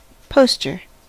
Ääntäminen
Synonyymit bill Ääntäminen US : IPA : [pəʊ.stə(r)] Haettu sana löytyi näillä lähdekielillä: englanti Käännös Konteksti Substantiivit 1.